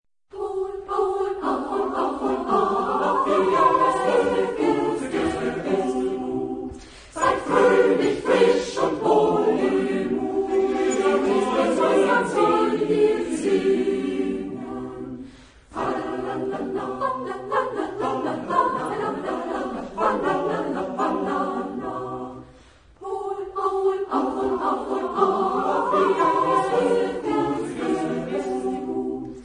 Genre-Style-Form: Drinking song ; Madrigal ; Lied ; Renaissance ; Secular
Type of Choir: SATB  (4 mixed voices )
Tonality: D major
sung by Kammerchor des Pestalozzi-Gymnasiums München
Discographic ref. : 3.Deutscher Chorwettbewerb, 1990